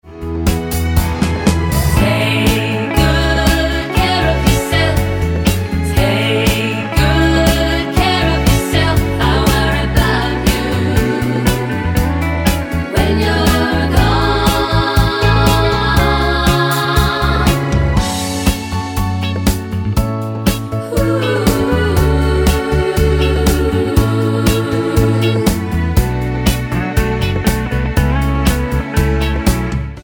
--> MP3 Demo abspielen...
Tonart:A mit Chor